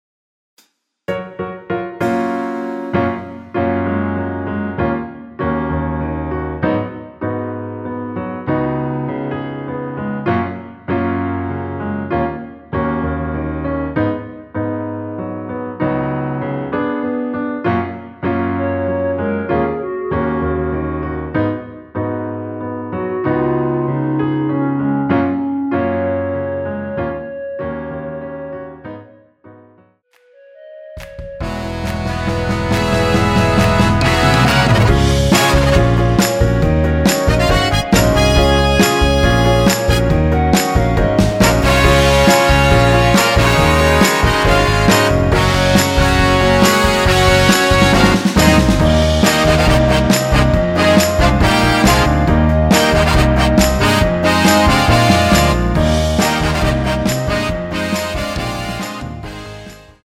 전주 없이 시작 하는 곡이라 인트로 만들어 놓았습니다.
원키에서(-1)내린 멜로디 포함된 MR입니다.
F#
앞부분30초, 뒷부분30초씩 편집해서 올려 드리고 있습니다.
중간에 음이 끈어지고 다시 나오는 이유는